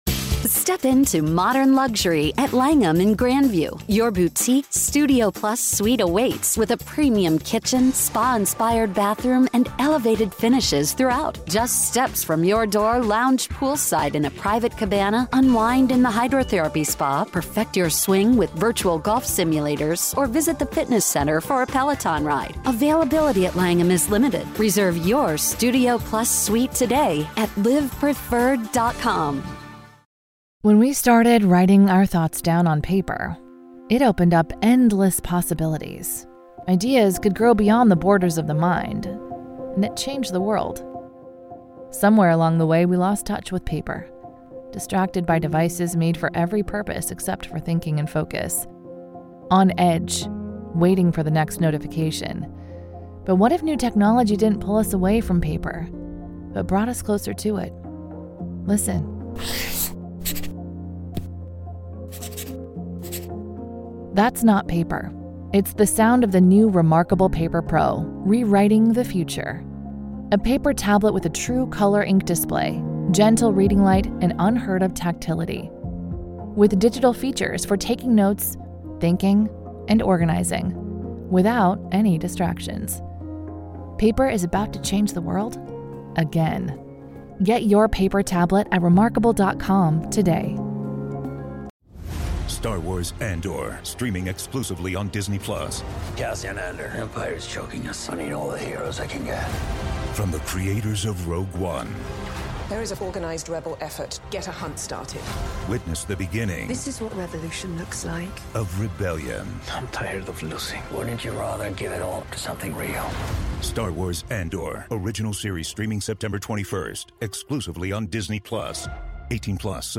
Scarlett’s book choices are: ** Girls in Love by Jacqueline Wilson ** I Capture the Castle by Dodie Smith ** Dawn by Octavia Butler ** Mrs Dalloway by Virginia Woolf ** The Accidental by Ali Smith Vick Hope, multi-award winning TV and BBC Radio 1 presenter, author and journalist, is the host of season five of the Women’s Prize for Fiction Podcast. Every week, Vick will be joined by another inspirational woman to discuss the work of incredible female authors.